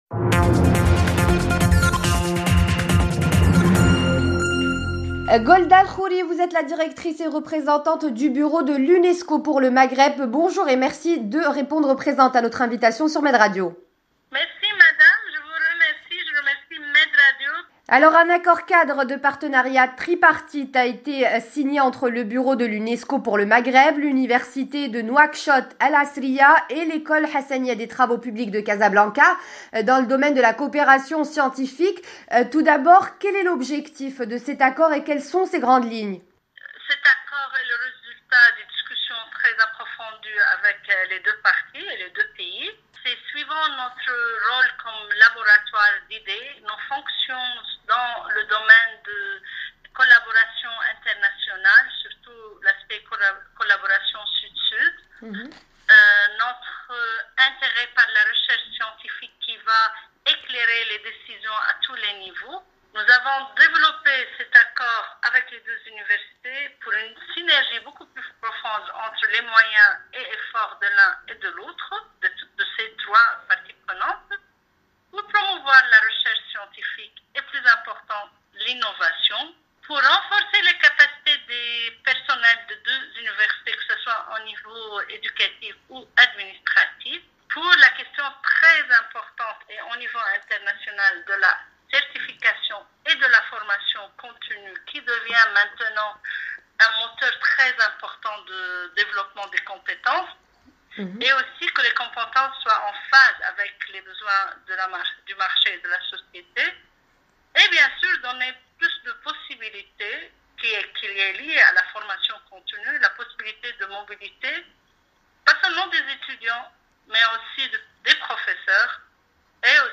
ENTRETIEN / Golda El Khoury, Directrice et Représentante du Bureau de l’UNESCO pour le Maghreb